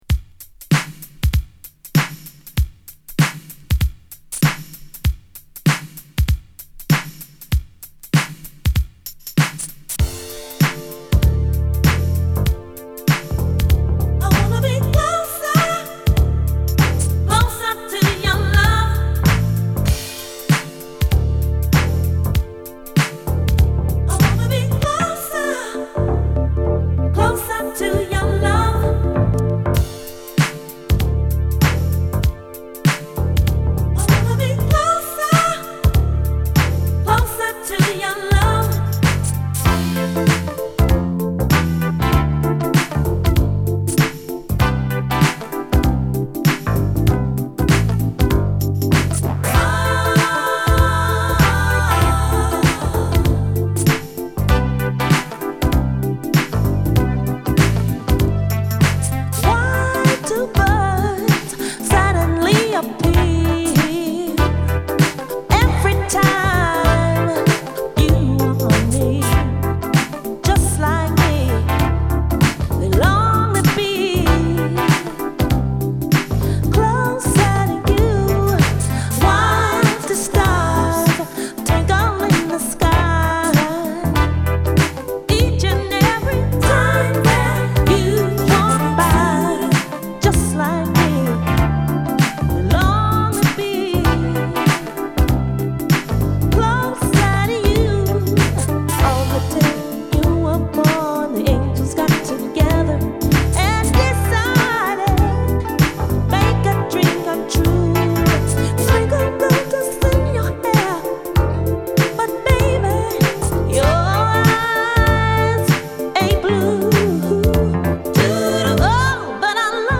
86年のダンスクラシック！